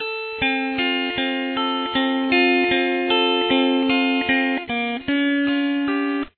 Play this intro with a capo on the third fret.